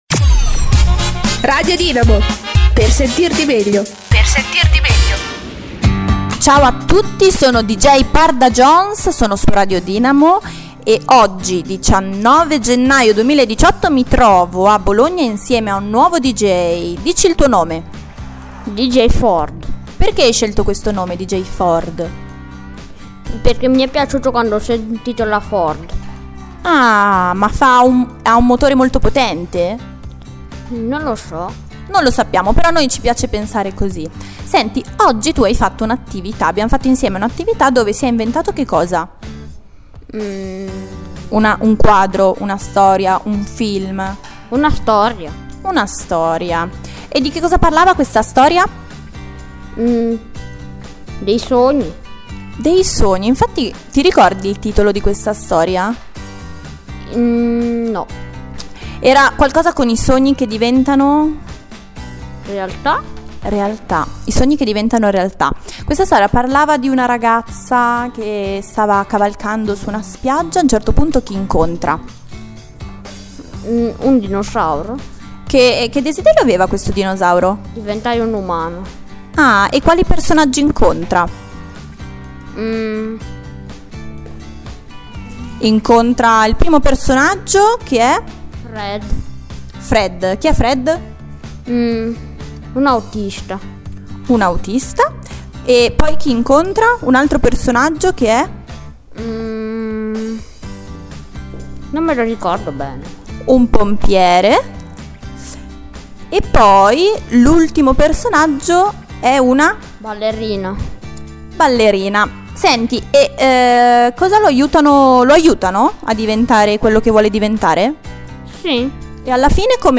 MINI-INTERVISTA!!